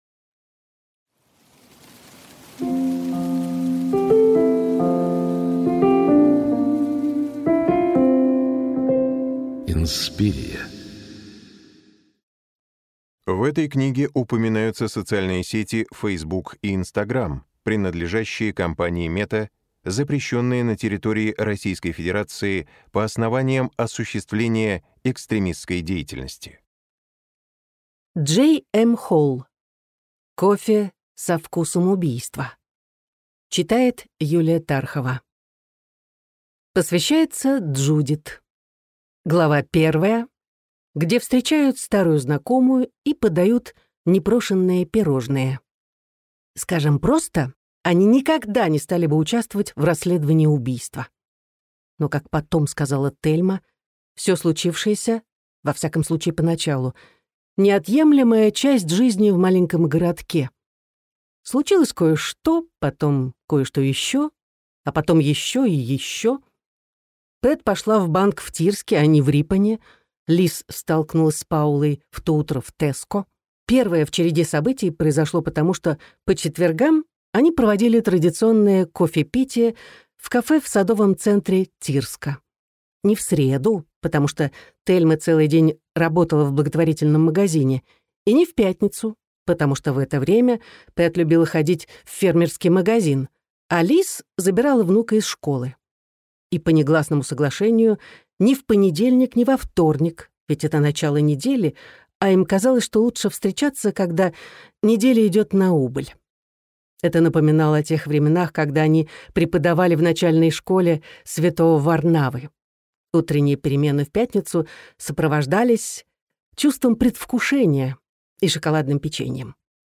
Аудиокнига Кофе со вкусом убийства | Библиотека аудиокниг
Прослушать и бесплатно скачать фрагмент аудиокниги